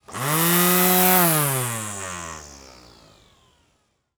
STRIMMER_On_Run_Off_mono.wav